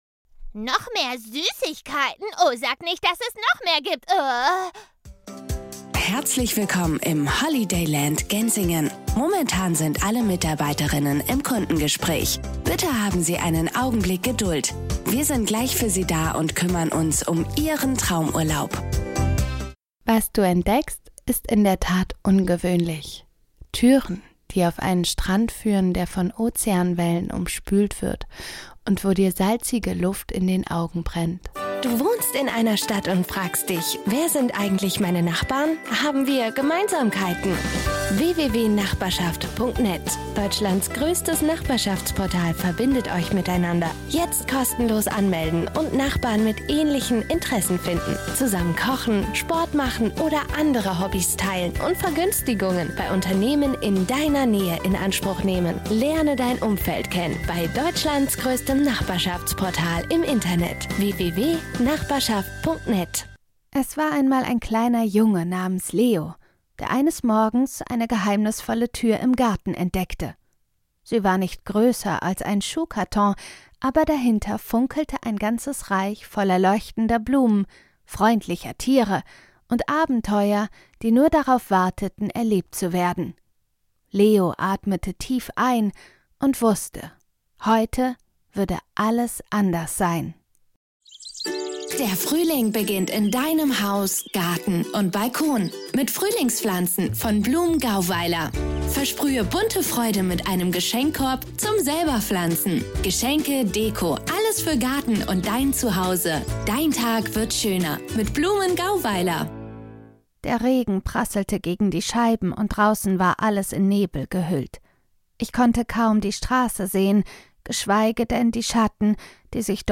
Voice Artists - Young